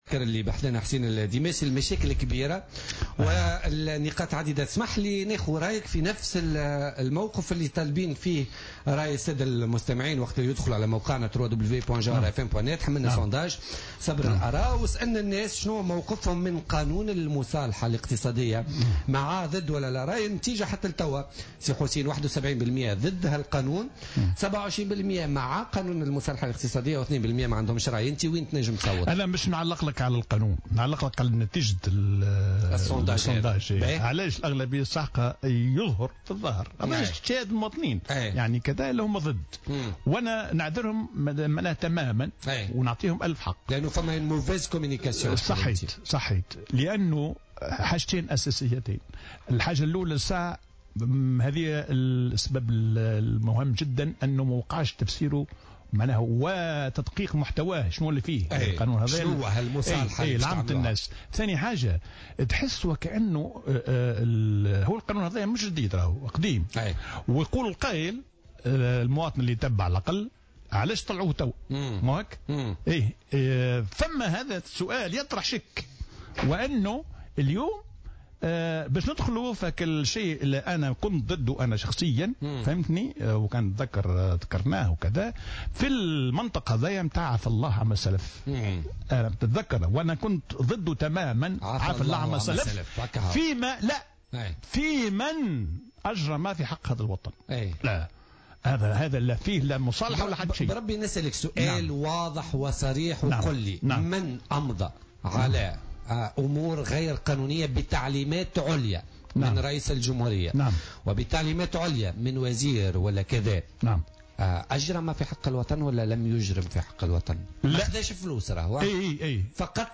قال الخبير الاقتصادي ووزير المالية الأسبق حسين الديماسي ضيف "بوليتكا" اليوم الجمعة، إن أغلبية الشعب التونسي رفضت مشروع قانون المصالحة الاقتصادية لأنه تم التسويق له بصفة خاطئة ولم يقع تفسيره وتدقيق محتواه للعموم، حيث ينص المشروع على المحاسبة أكثر من المصالحة مع تسريع الإجراءات القانونية.